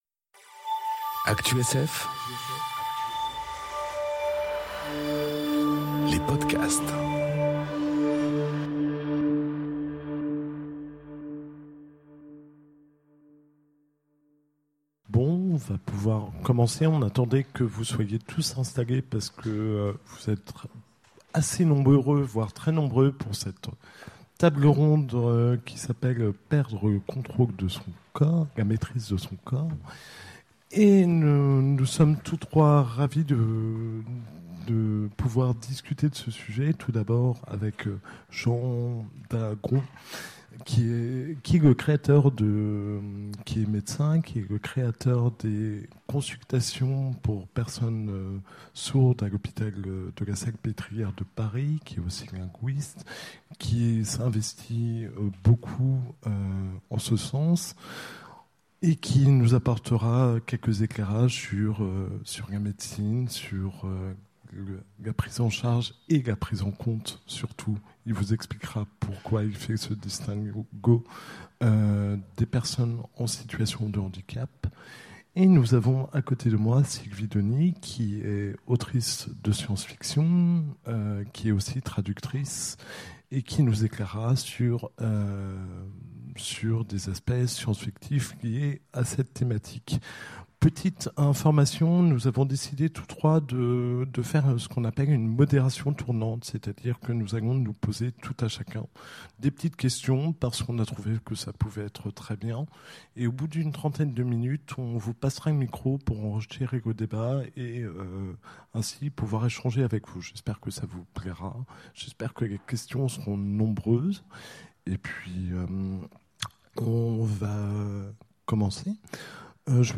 Conférence Perdre la maîtrise de son corps enregistrée aux Utopiales 2018